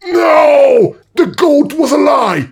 goatfree01.ogg